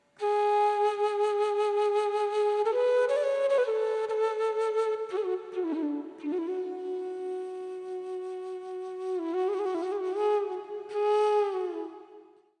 bansurī
Le Bansurī est une flûte traversière fabriquée à partir d'un seul morceau de bambou avec six ou sept trous.
bansuri.mp3